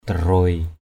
/d̪a-rʊoɪ/ 1.